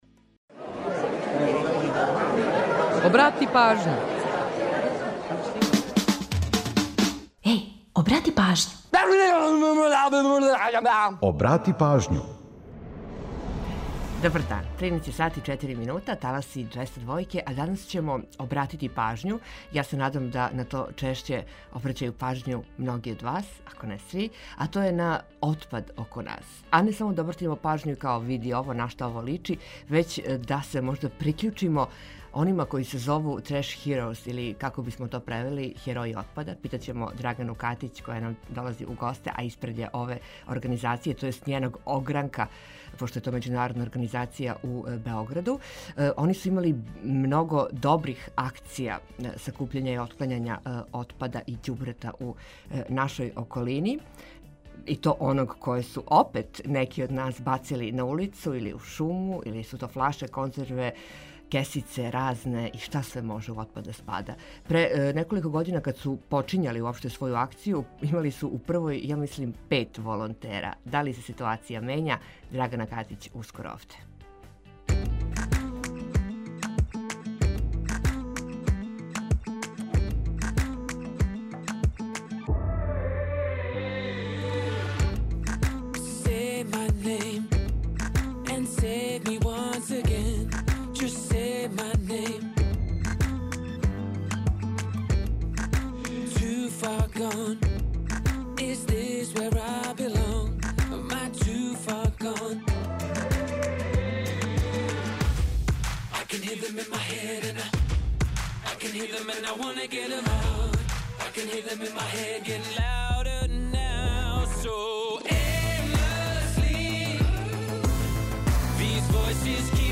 А у наставку ту је и пола сата резервисаних за домаћицу, то јест музику из Србије и региона, затим минути за једну посебну причу о песми, за актуелности са светских музичких топ-листа, занимљивости, сервисне информације и нашег репортера.